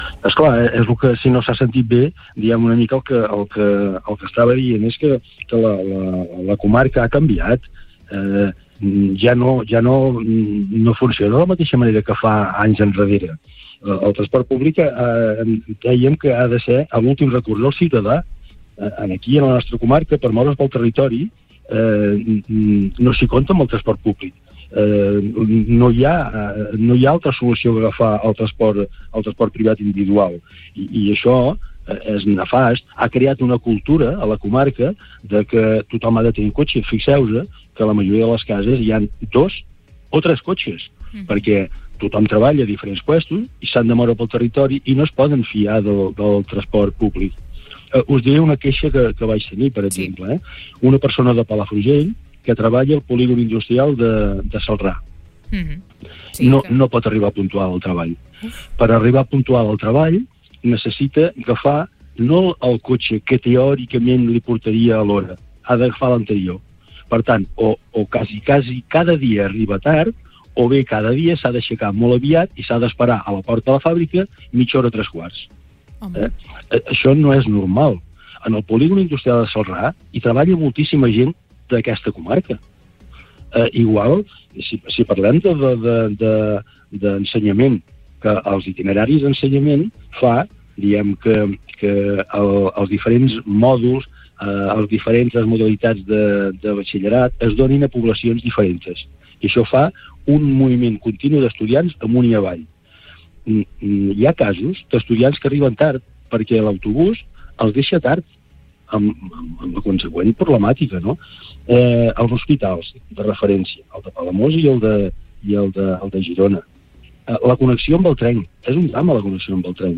Supermatí - entrevistes
I per parlar de totes aquestes reivindicacions ens ha visitat al Supermatí el Síndic de Greuges de Palafrugell, Francesc Almagro.